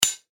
metal-clink.mp3